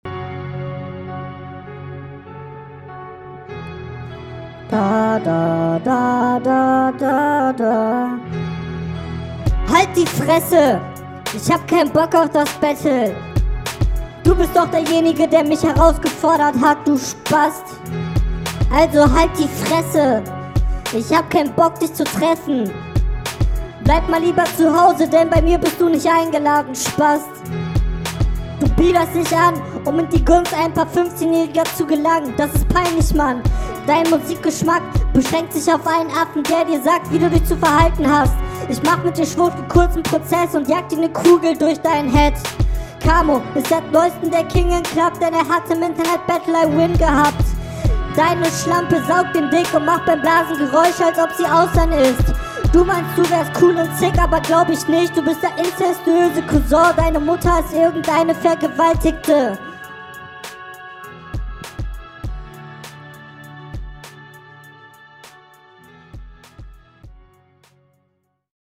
eine gepitchte Stimme die Crashout geht ... mehr nicht